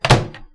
微波炉门关闭2
描述：我正在制作爆米花，所以我决定在做的时候听一些声音。